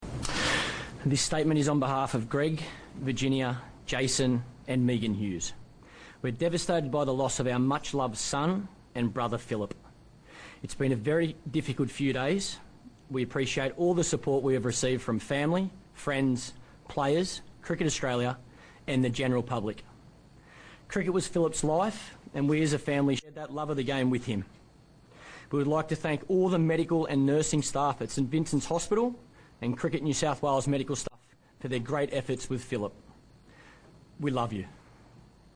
Michael Clarke reads statement on behalf of Hughes family